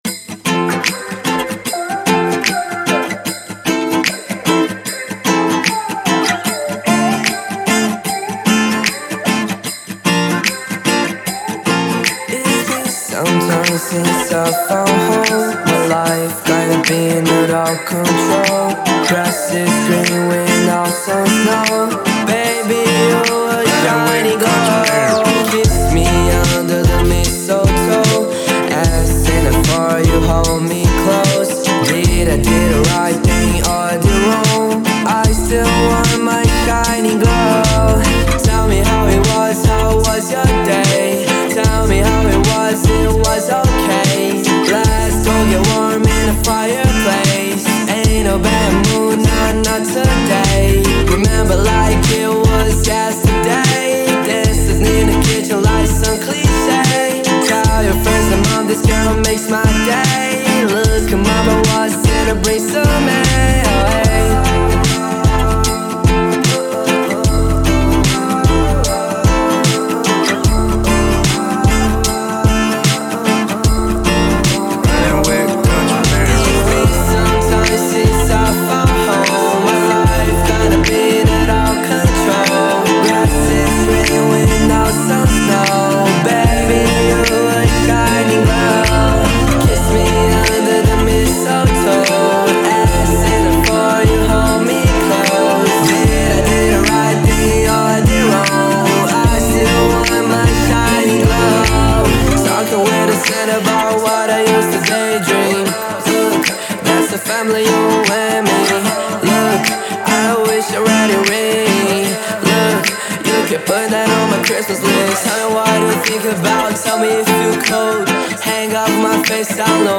Em um estilo pop anos 2010